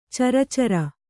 ♪ cara cara